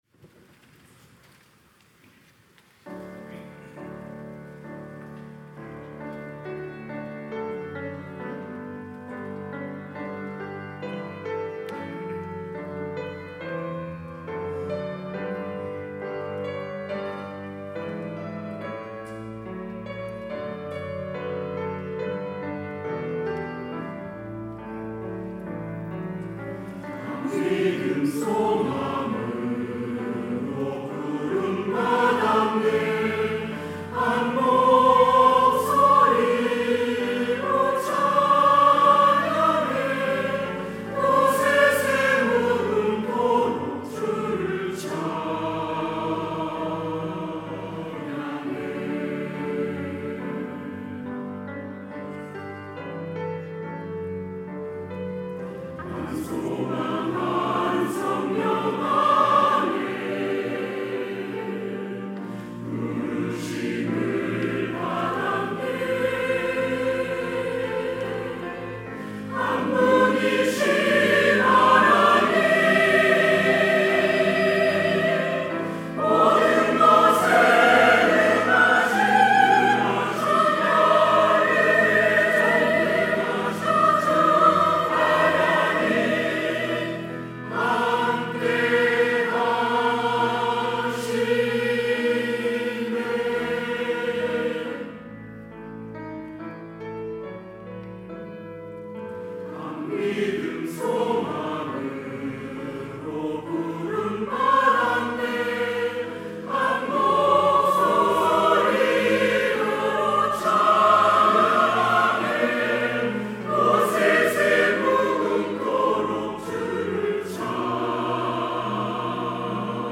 시온(주일1부) - 한 믿음, 한 소망, 한 주님
찬양대